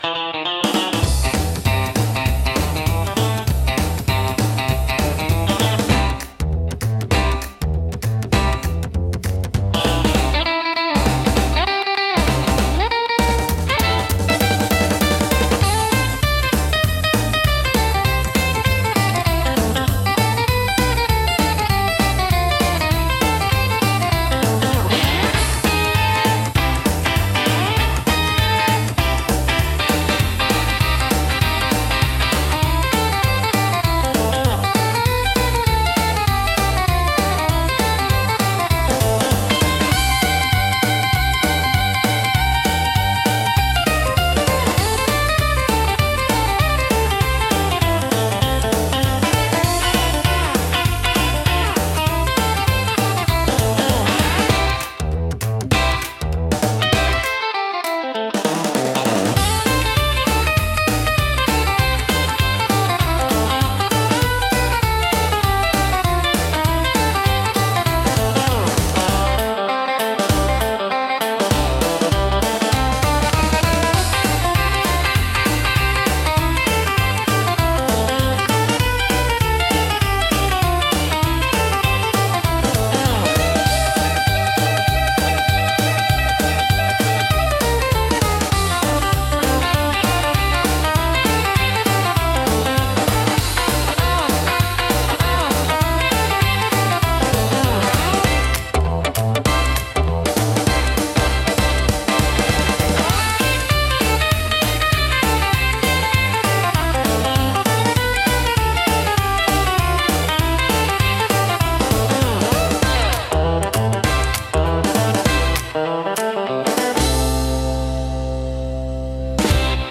懐かしさと若々しいエネルギーが共存し、楽しい空気づくりに効果的なジャンルです。